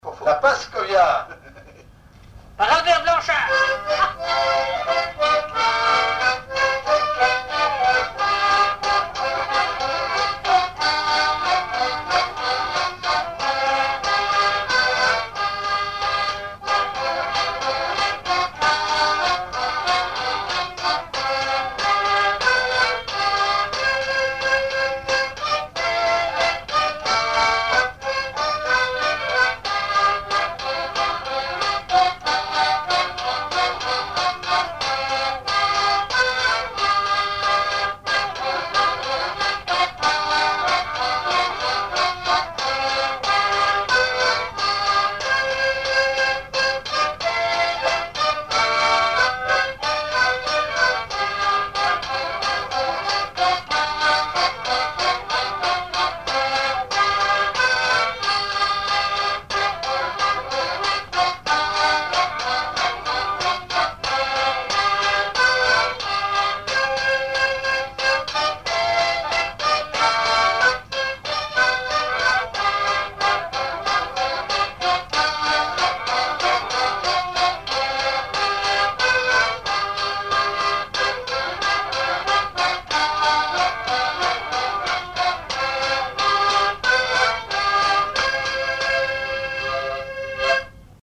danse : paskovia
enregistrements du Répertoire du violoneux
Pièce musicale inédite